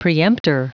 Prononciation du mot preemptor en anglais (fichier audio)
Prononciation du mot : preemptor